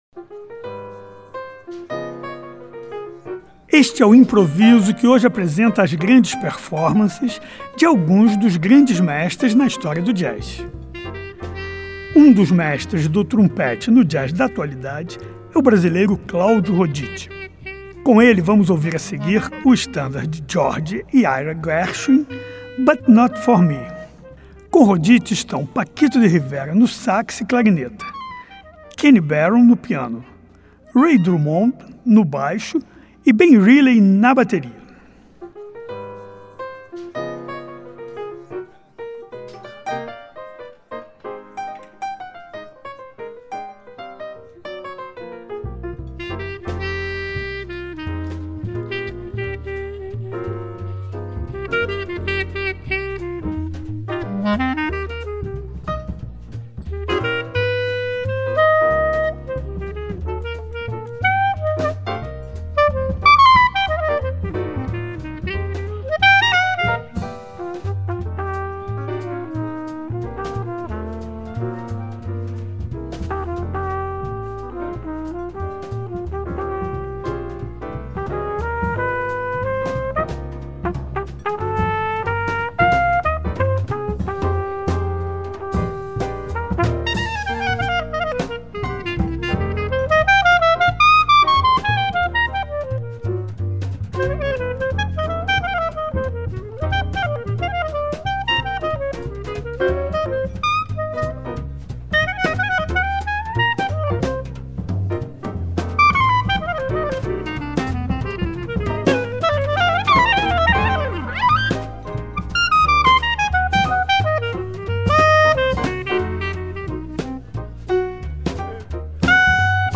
ao vivo, no melhor estilo das jam sessions.
É onde aparece o melhor do jazz.